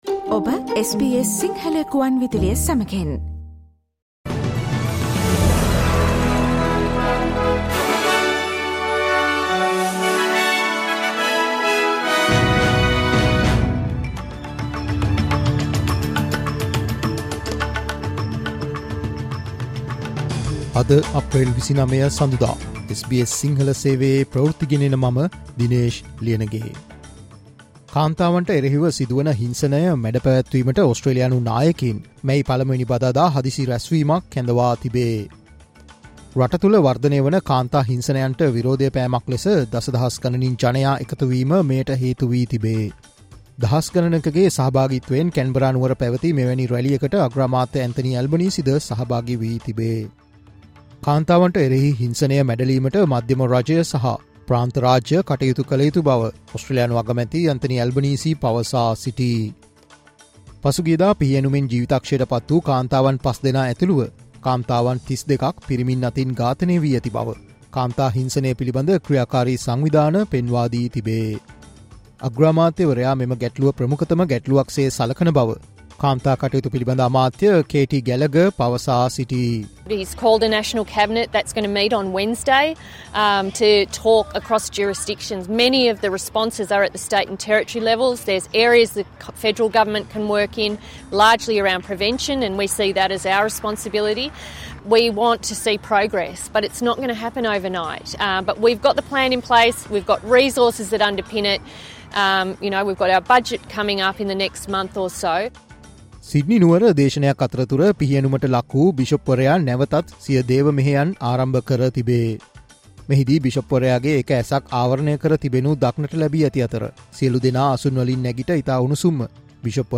Australian news in Sinhala, foreign and sports news in brief - listen, Sinhala Radio News Flash on Monday 29 April 2024